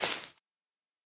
Minecraft Version Minecraft Version 25w18a Latest Release | Latest Snapshot 25w18a / assets / minecraft / sounds / block / sponge / step4.ogg Compare With Compare With Latest Release | Latest Snapshot
step4.ogg